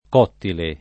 vai all'elenco alfabetico delle voci ingrandisci il carattere 100% rimpicciolisci il carattere stampa invia tramite posta elettronica codividi su Facebook cottile [ k 0 ttile ] agg. — latinismo per «fatto di mattoni»